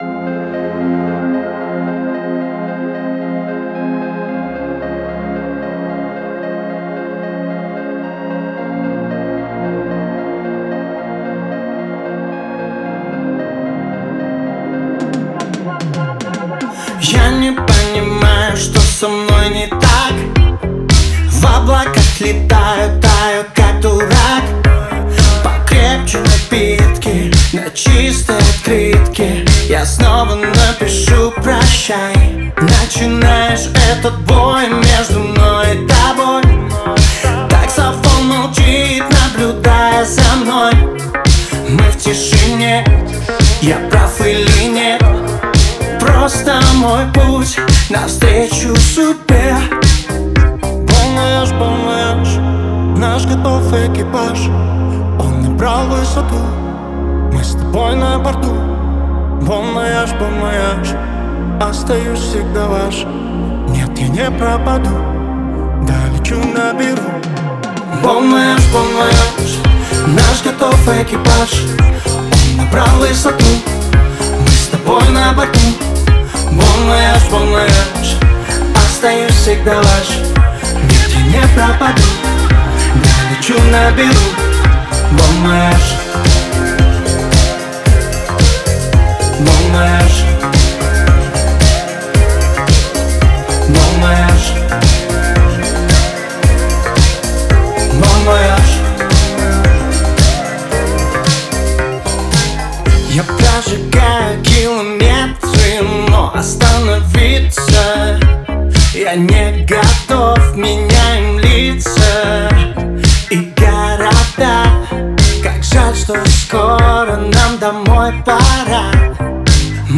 в жанре поп-рок, наполненная энергией и меланхолией